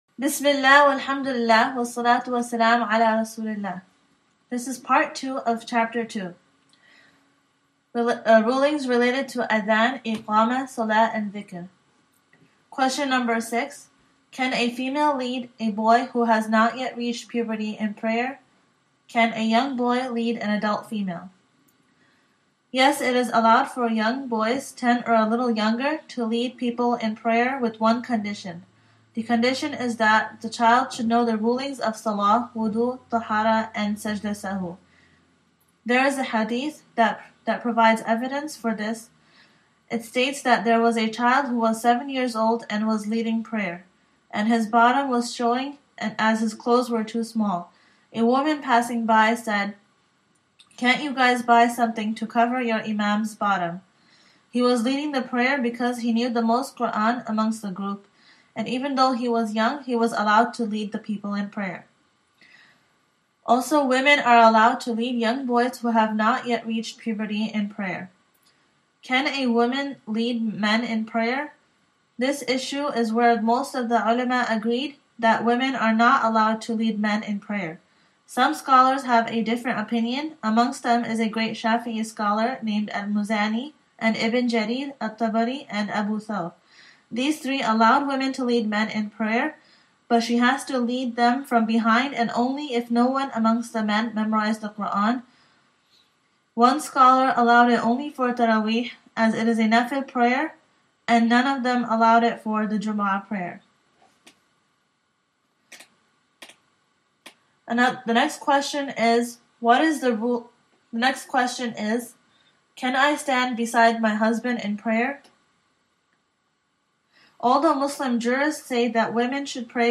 Study Session for Chapter 2 (Part 2) of the AlMaghrib Institute seminar, “Complicated”, which discussed women’s modern Fiqh issues.